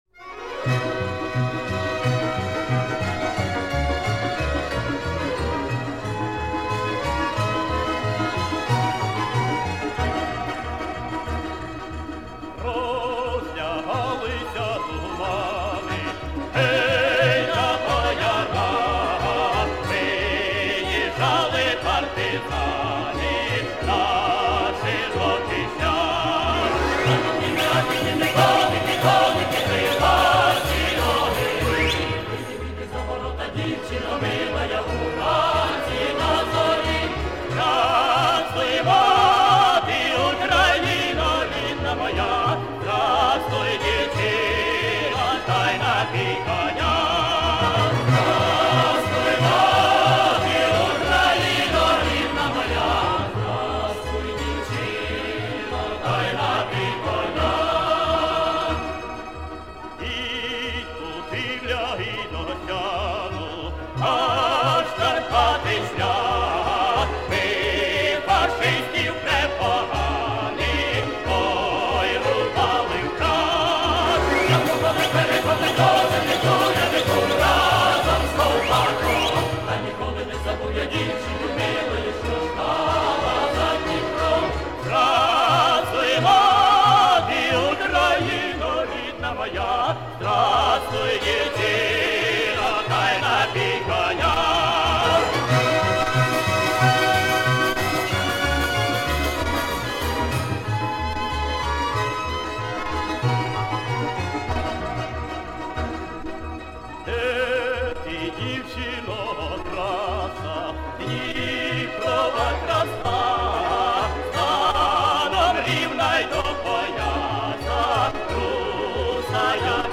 Запись 1970-х годов.